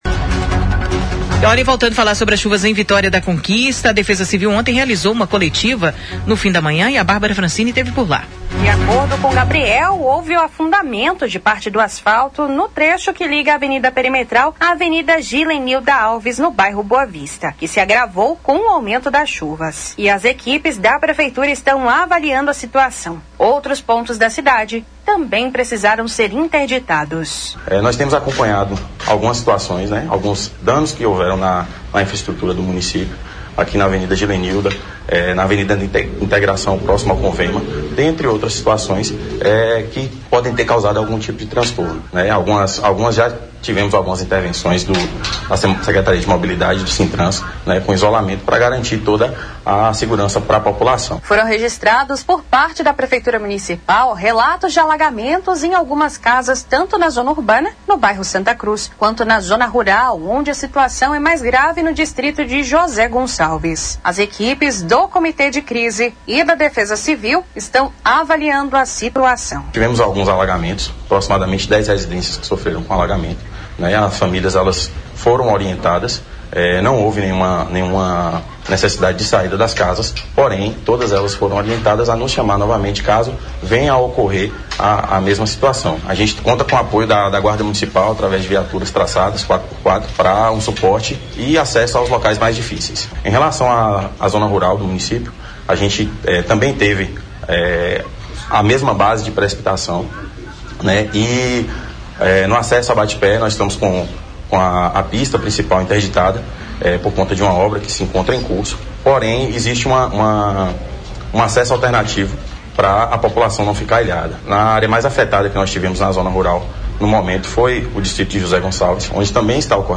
Entrevista Coletiva | Defesa Civil atualiza dados dos impactos das chuvas em Vitória da Conquista
DC-01-Defesa-Civil.mp3